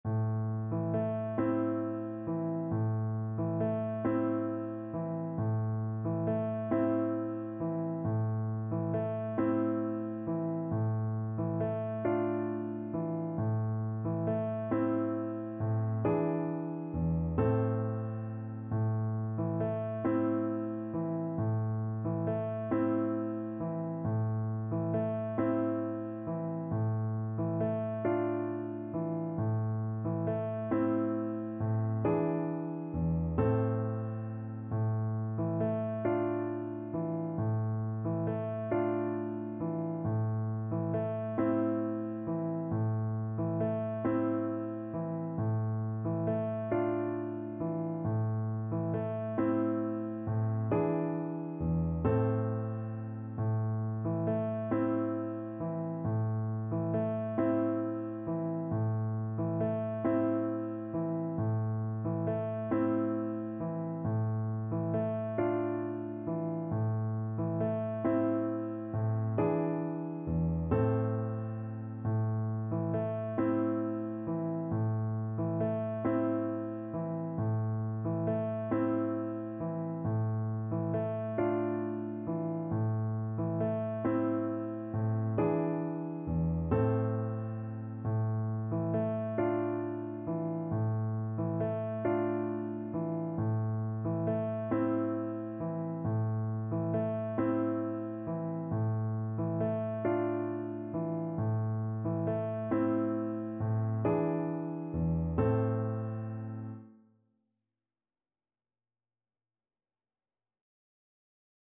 Traditional Music of unknown author.
A minor (Sounding Pitch) (View more A minor Music for Flute )
6/8 (View more 6/8 Music)
Gently rocking .=c.45
Turkish